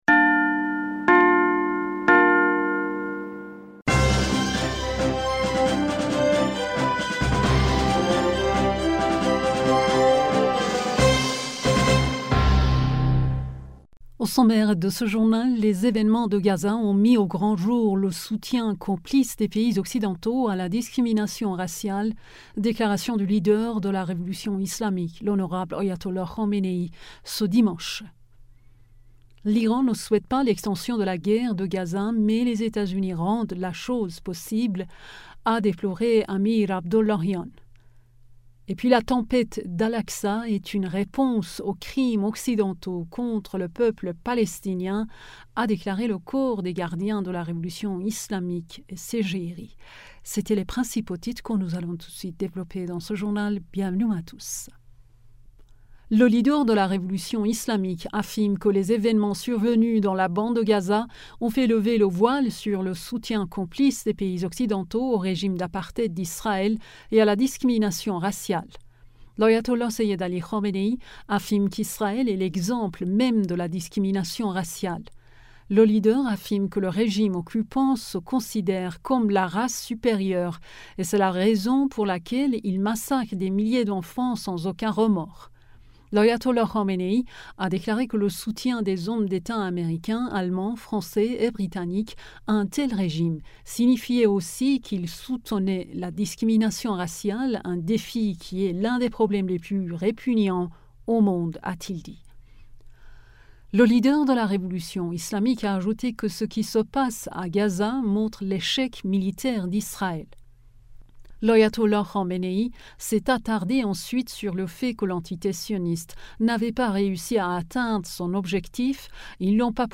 Bulletin d'information du 19 Novembre 2023